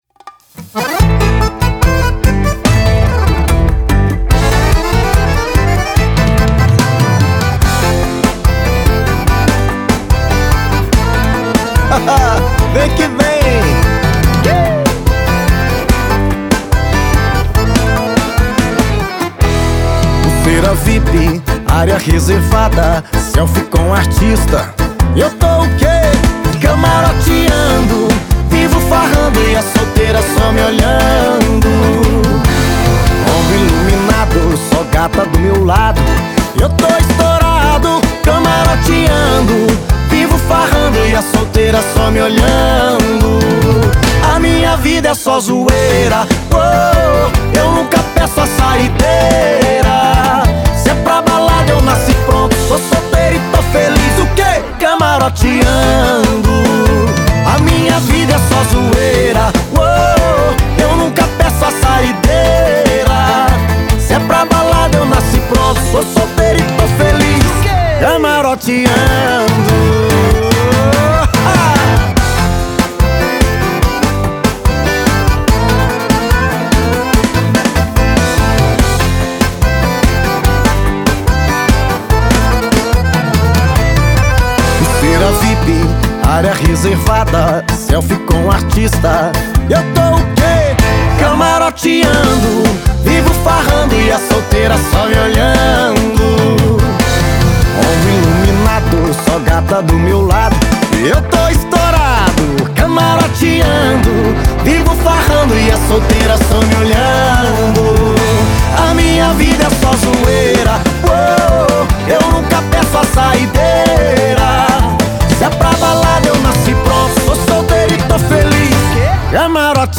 sertanejos